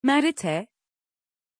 Aussprache von Merete
pronunciation-merete-tr.mp3